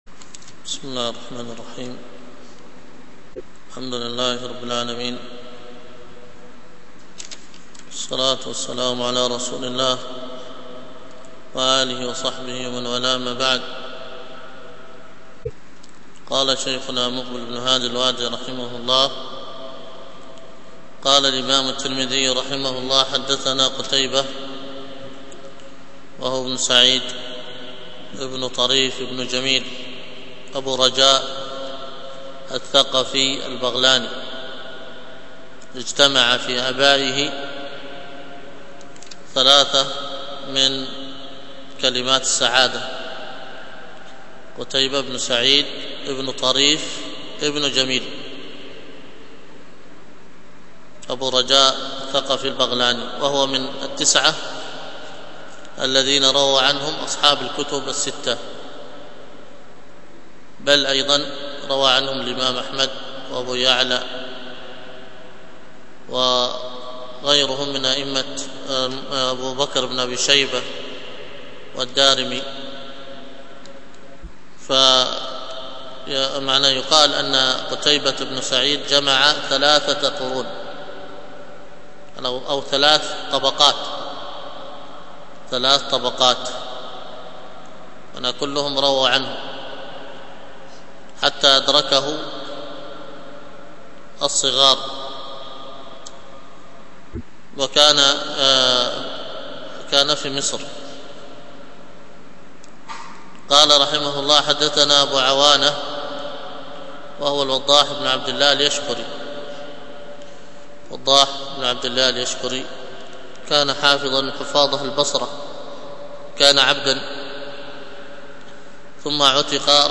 الدرس في الصحيح المسند مما ليس في الصحيحين 18، ألقاها